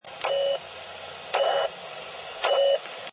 Ils ont été effectués à l'aide d'un camescope placé près du haut-parleur d'un MVT-7100.
Enregistrement 1 : SSB. Parasite émis par une installation militaire suisse à une trentaine de kilomètres. Période de 1,0 seconde, modulation stable.